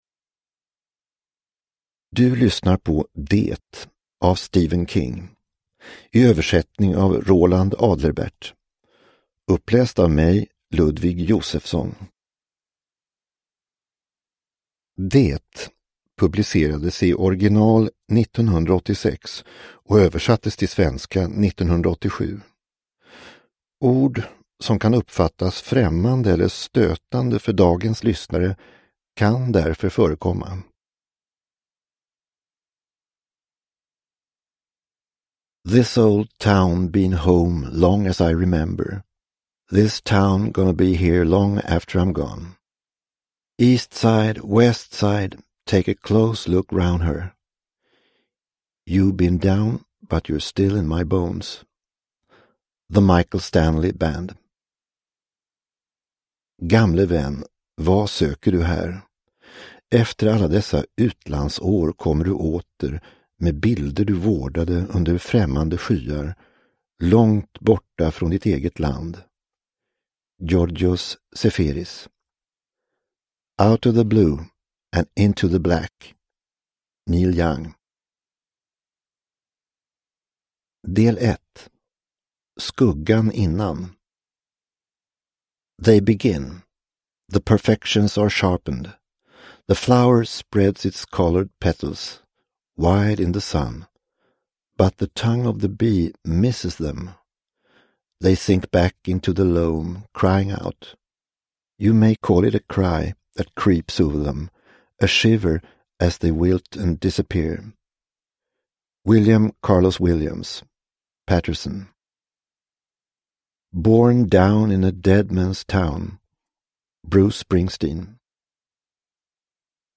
Det – Ljudbok – Laddas ner